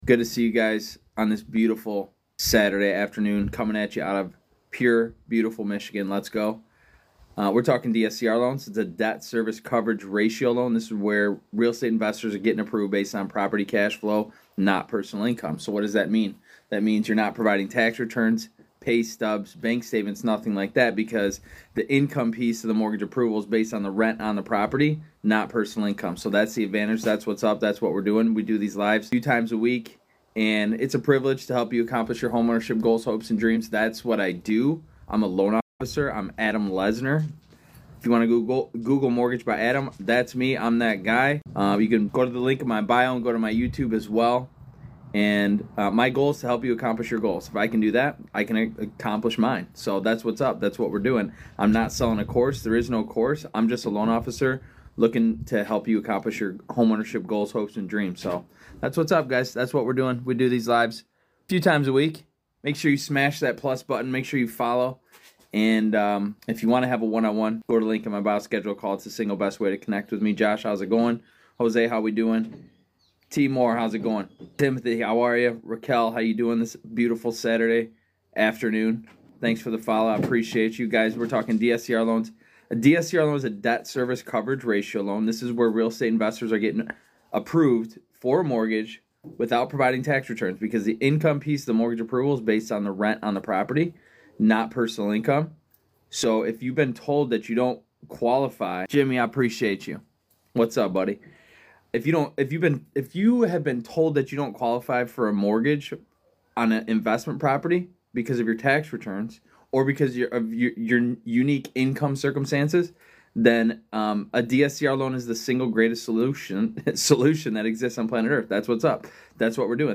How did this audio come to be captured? In this TikTok Livestream recap, we dive deep into DSCR loans—explaining how they work, who they’re for, and why they’re […]